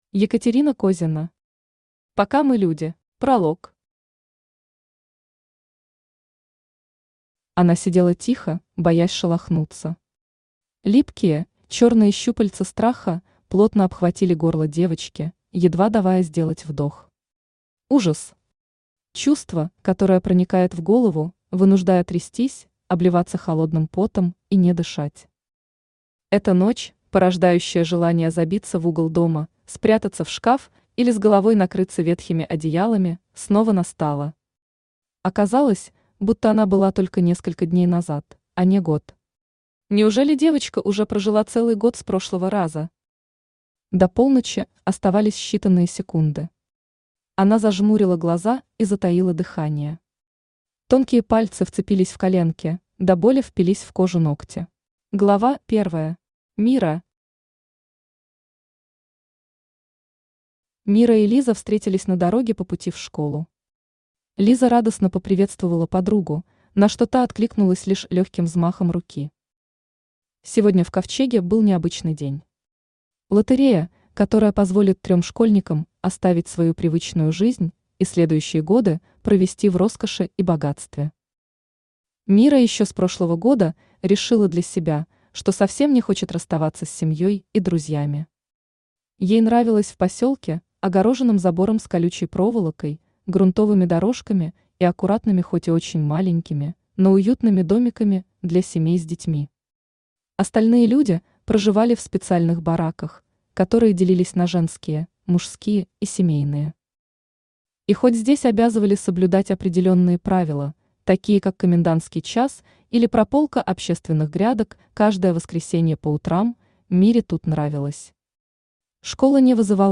Аудиокнига Пока мы люди | Библиотека аудиокниг
Aудиокнига Пока мы люди Автор Екатерина Козина Читает аудиокнигу Авточтец ЛитРес.